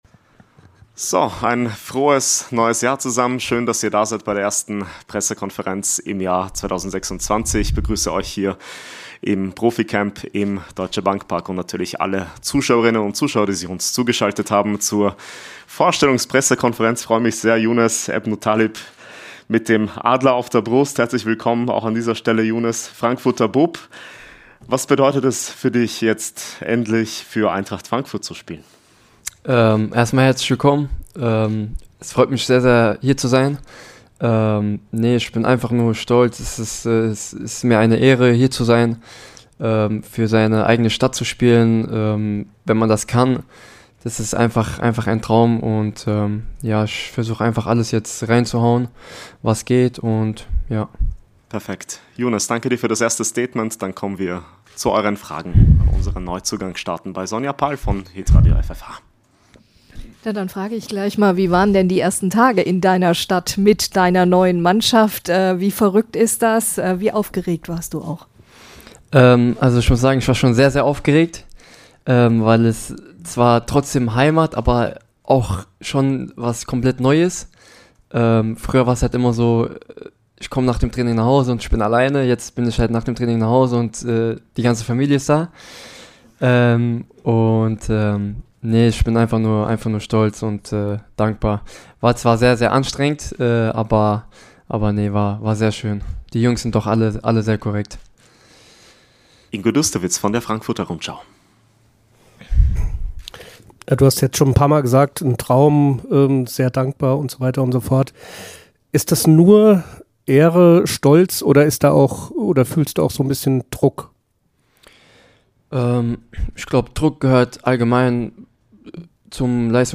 Die Pressekonferenz aus dem ProfiCamp.